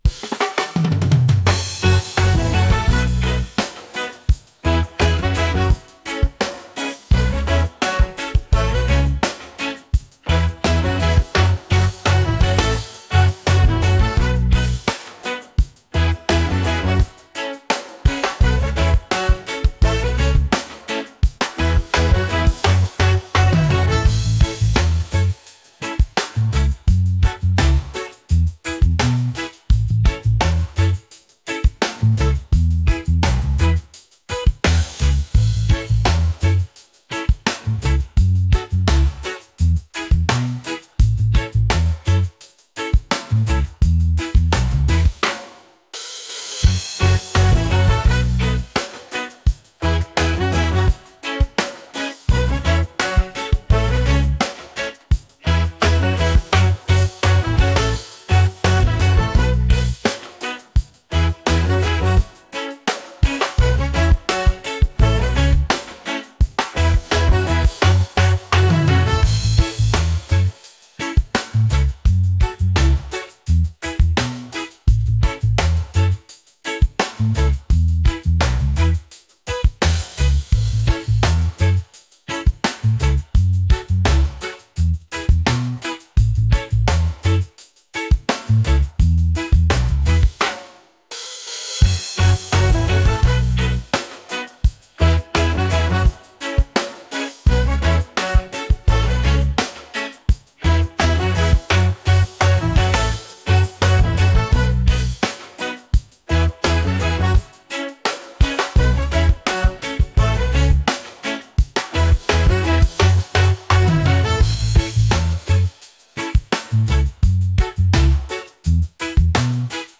upbeat | reggae | catchy